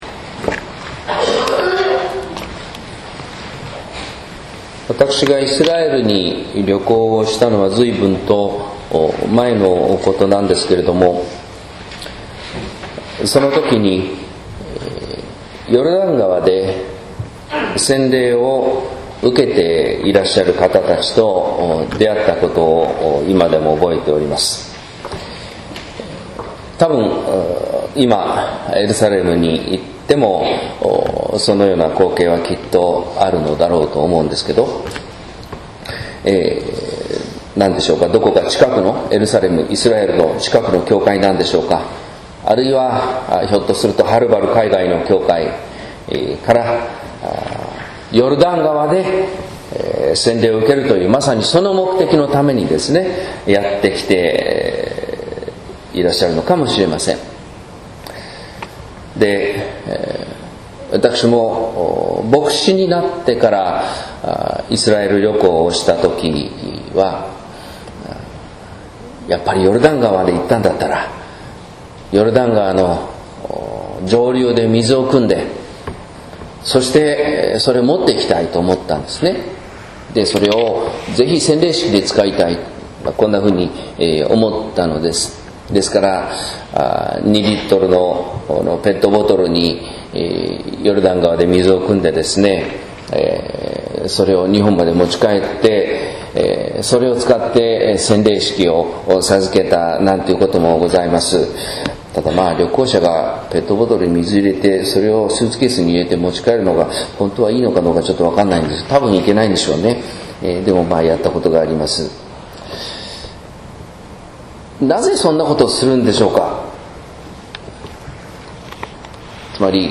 説教「洗礼と聖霊」（音声版）